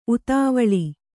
♪ utāvaḷi